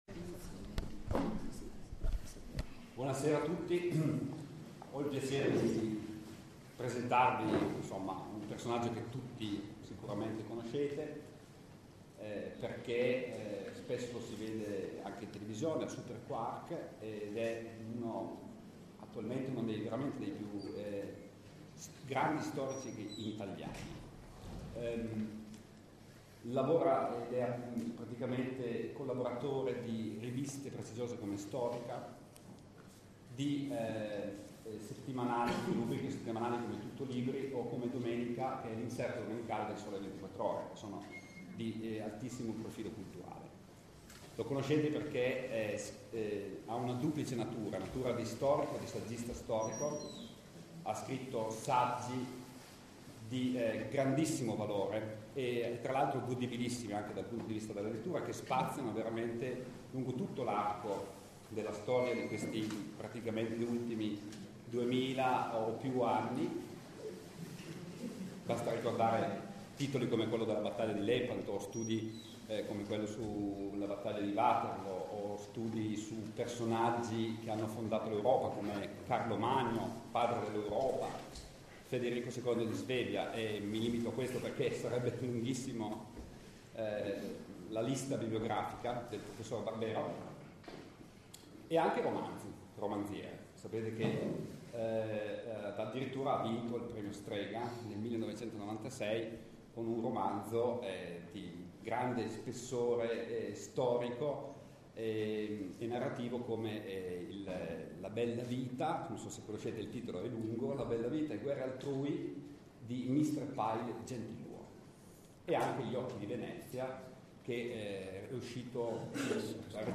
SCARICA LA RELAZIONE DI ALESSANDRO BARBERO IN FORMATO MP3:
L’incontro si è tenuto venerdì 25 marzo 2011 alle 21,00 presso il Castello di Cisterna d’Asti organizzato dal dal Polo Cittattiva per l’Astigiano e l’Albese - di cui la Direzione Didattica di S. Damiano d’Asti è capofila – in collaborazione con il Museo Arti e Mestieri di un Tempo di Cisterna d’Asti. more…